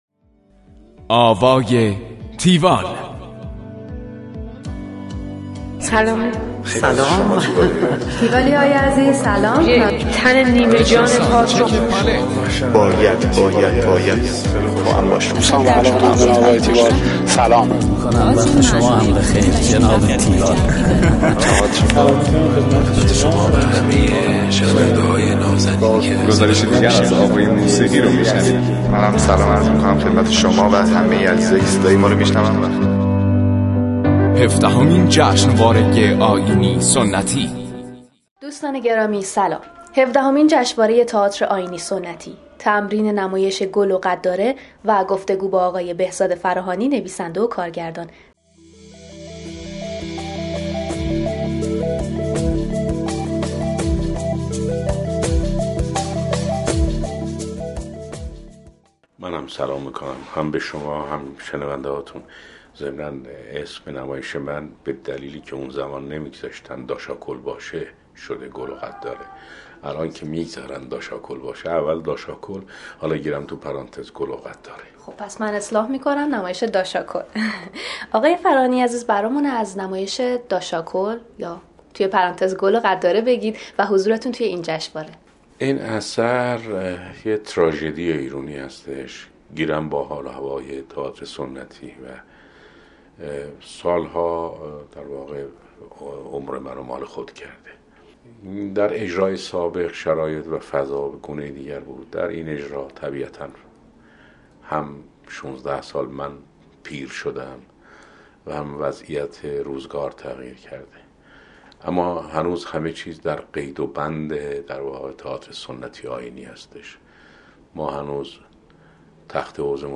تیوال تئاتر | آواها | گفتگوی تیوال با بهزاد فراهانی